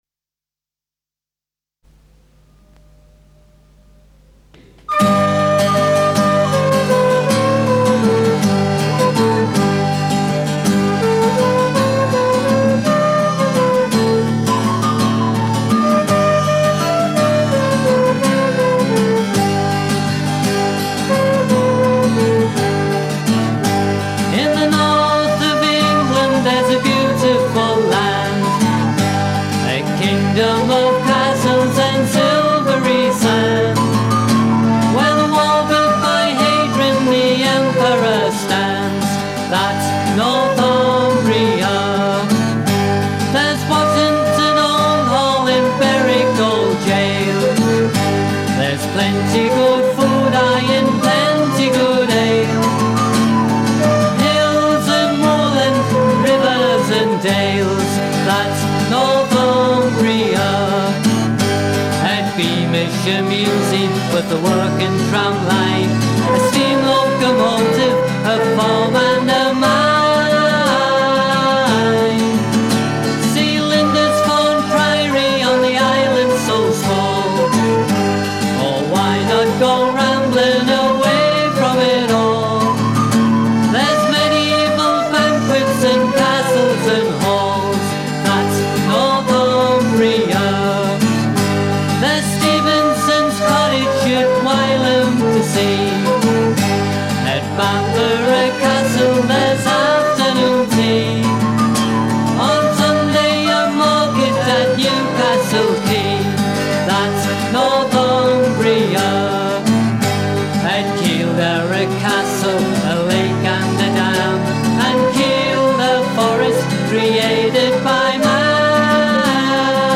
Vocals & Guitar
Recorder
Flute
Piano Accordion.